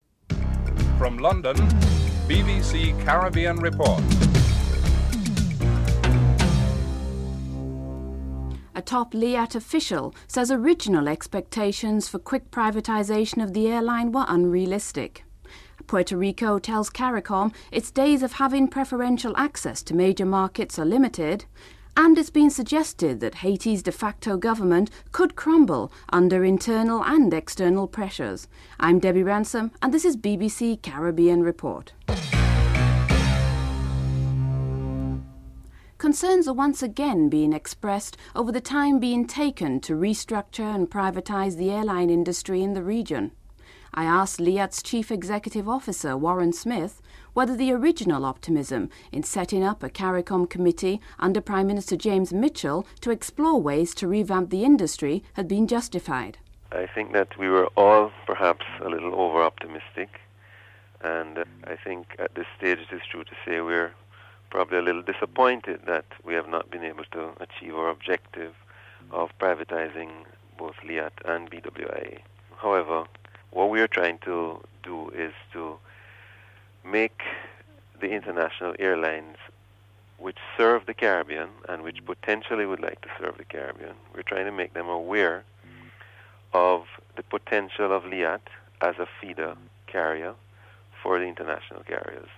1. Headlines (00:00-00:32)
Incoming CARICOM Secretary General Edwin Carrington supports the move to dismantle the CET in its present form (08:41-11:05)